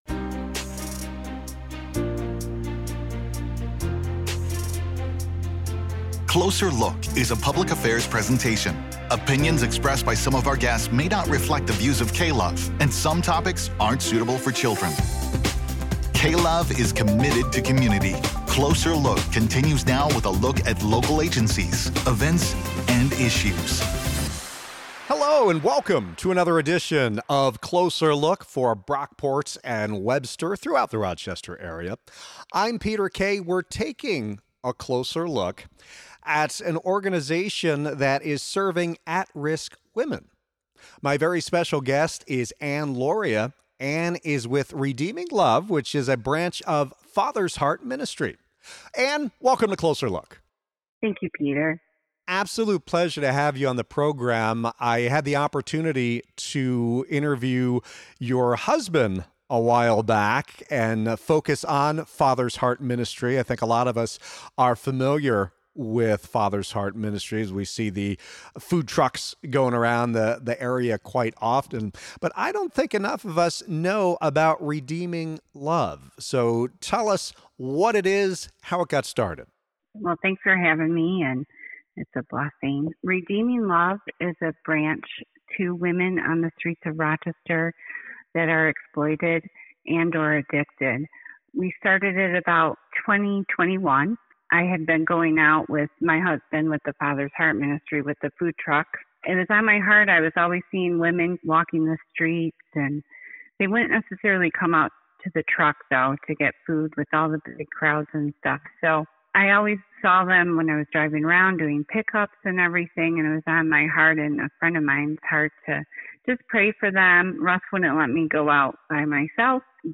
Radio/Podcast Interviews -